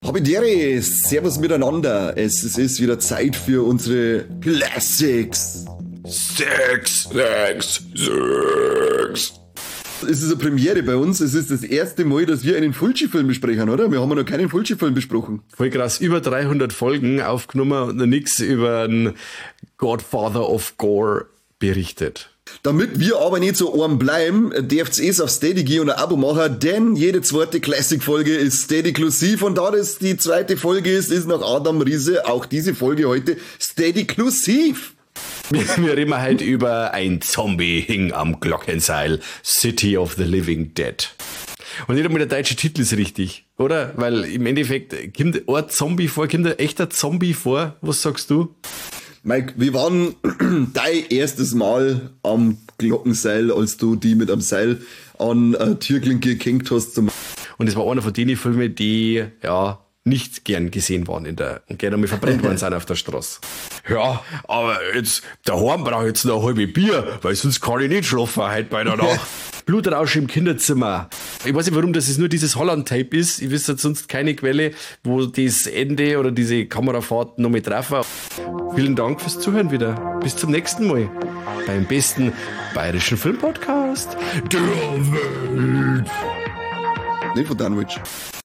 "Viva la Movielucion" ist der bayrische Podcast rund um die Themen Film, Serien, Games und alles was unterhält! Wie bei einer Stammtischrunde gehts in so mancher Sendung mit bayrischem Dialekt zu.